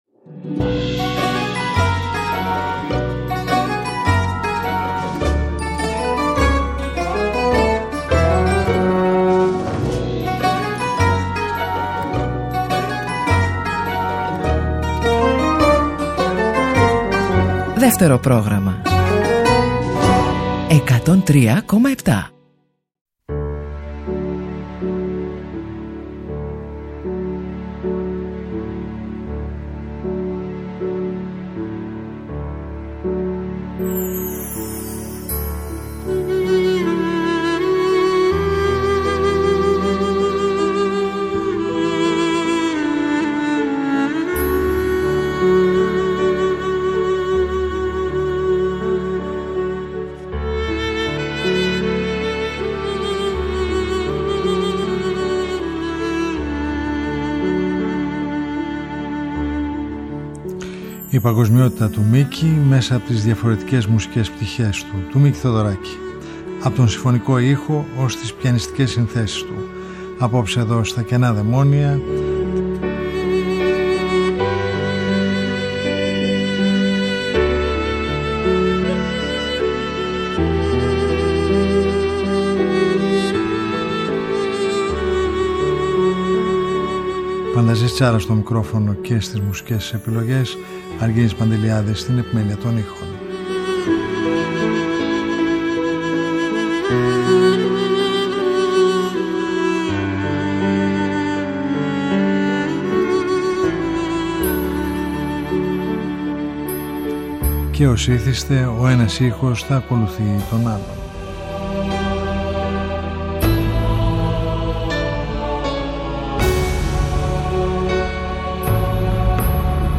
Το 2025 είναι χρονιά αφιερωμένη στα εκατό χρόνια του συνθέτη – θρύλου της Ελληνικής μουσικής. Ήχοι απ’ το συμφωνικό του έργο, τις ιδιαίτερες ερμηνείες του ίδιου και διαφόρων συγκροτημάτων, ως τις σπάνιες και ανέκδοτες πιανιστικές του συνθέσεις, ηχογραφημένες το 2018 στο στούντιο Ε της ΕΡΑ.